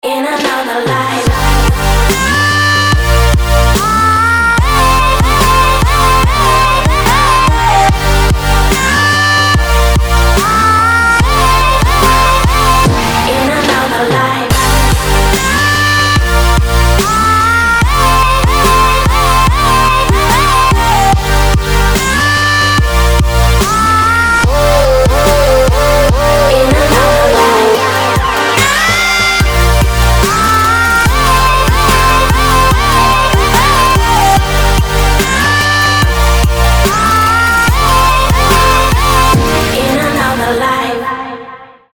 future bass